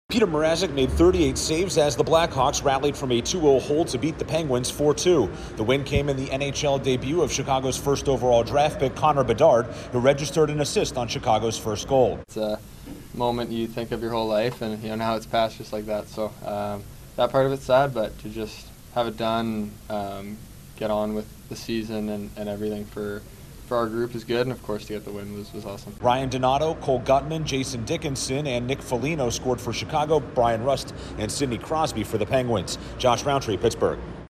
The Blackhawks open their season with a road win. Correspondent